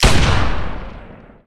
44Shoot.ogg